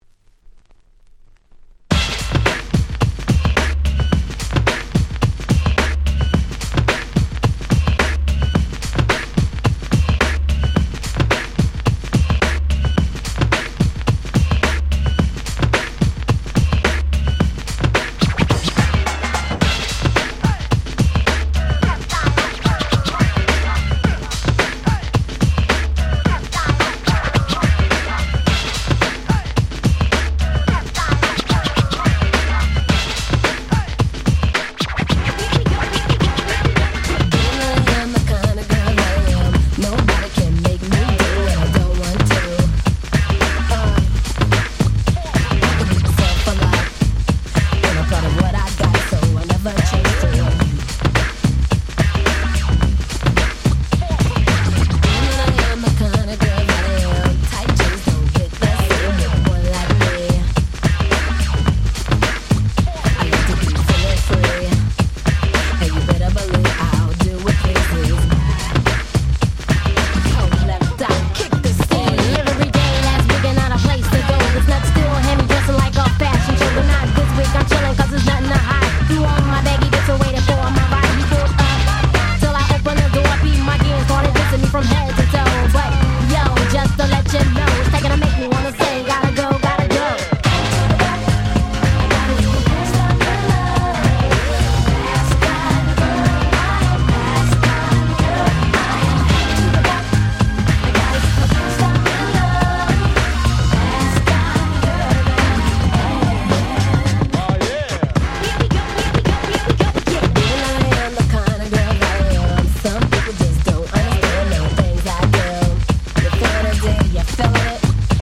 ウィキードミックス 90's Boom Bap ブーンバップ R&B 勝手にRemix 勝手にリミックス ミックス物